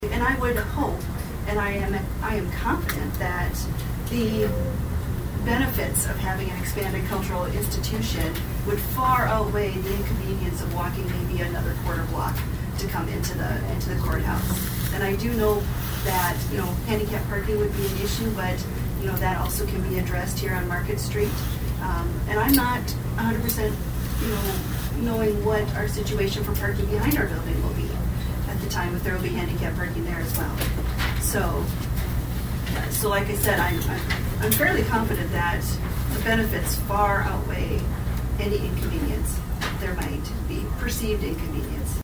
ABERDEEN, S.D.(HubCityRadio)- At Tuesday’s Brown County Commission meeting, the commissioners address a resolution dealing with the possible expansion of Dacotah Prairie Museum.